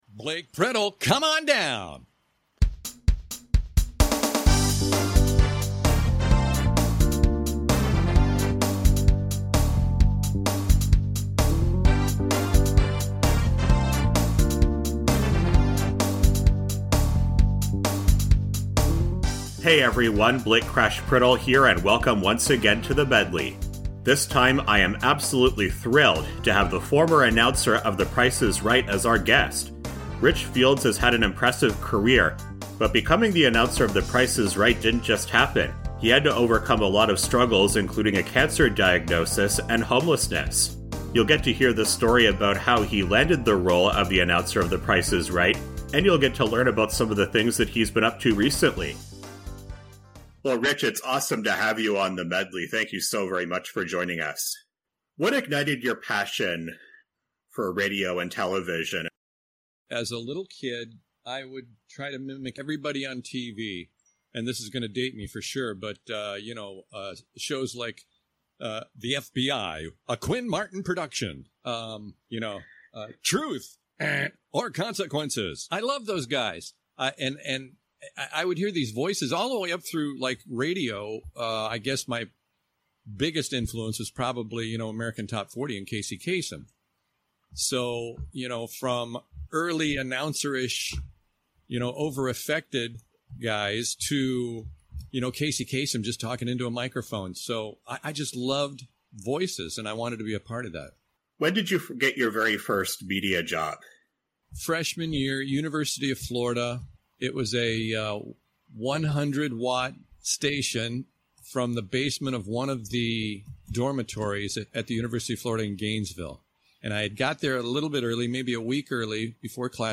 Its time to listen my my interview with the former announcer of The Price is Right Rich Fields. But he has done a lot more in his career besides that.